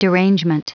Prononciation du mot derangement en anglais (fichier audio)
Prononciation du mot : derangement